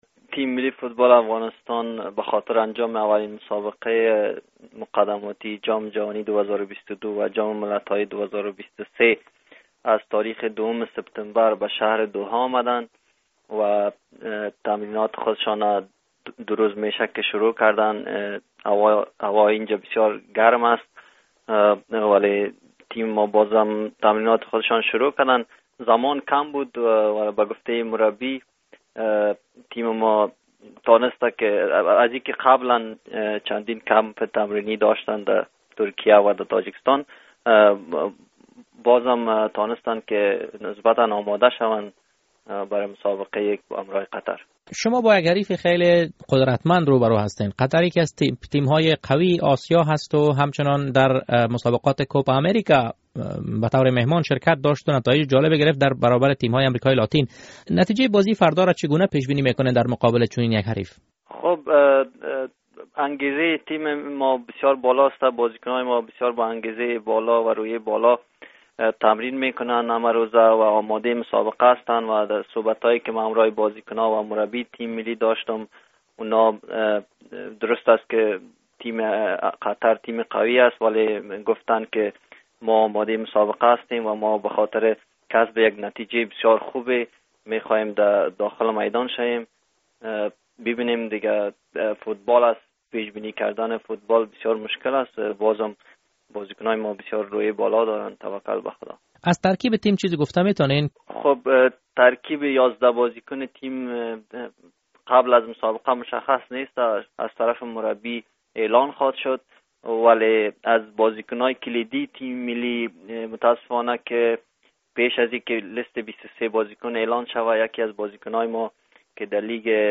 مصاحبه‌های ورزشی